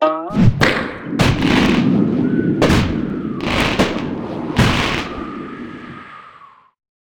slingshot.ogg